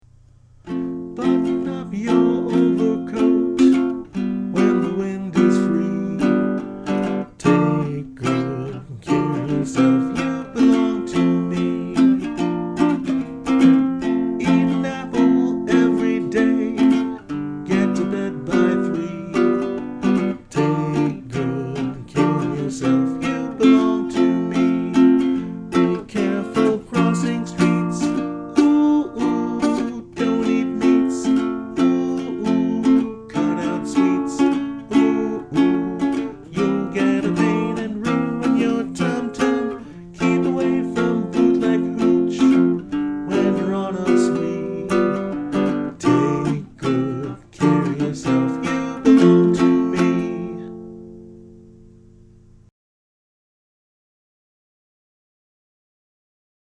Filed under Music, Personal, Ukelele